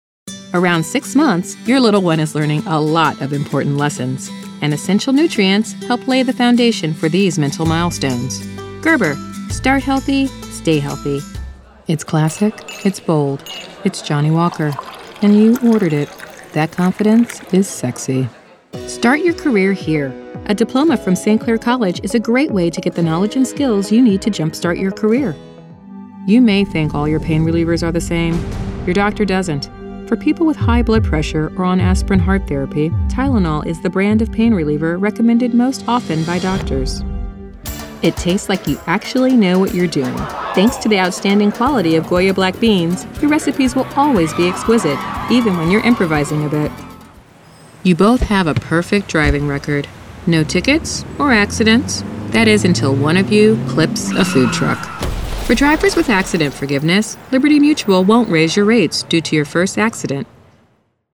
female non-union voice talent.
Need a warm, believable, friendly, female voice?